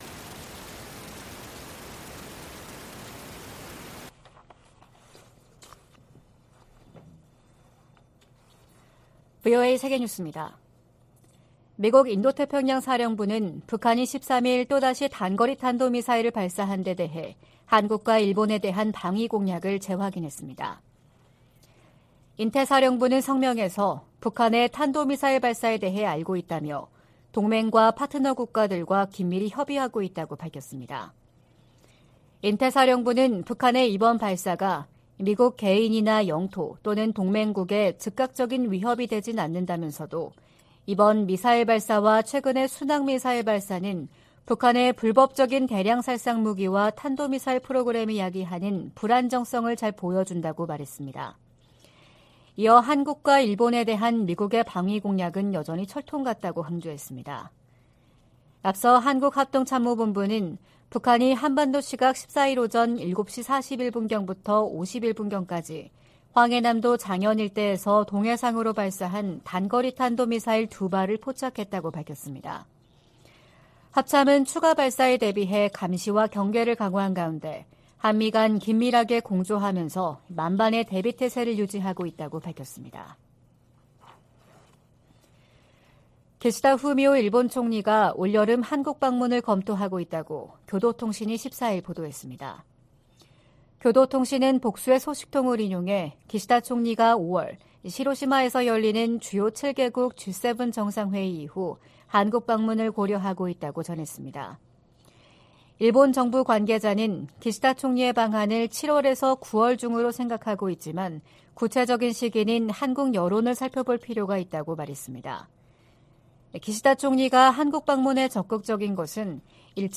VOA 한국어 '출발 뉴스 쇼', 2023년 3월 15일 방송입니다. 미국과 한국이 ‘자유의 방패’ 연합훈련을 실시하고 있는 가운데 북한은 미사일 도발을 이어가고 있습니다. 백악관은 한반도 안정을 저해하는 북한의 어떤 행동도 용납하지 않을 것이라고 경고했습니다. 한국을 주요 7개국(G7)에 포함시키는 방안을 추진해야 한다는 제안이 나온 데 전직 주한 미국대사들은 환영의 입장을 나타냈습니다.